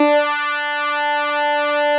Added synth instrument
snes_synth_050.wav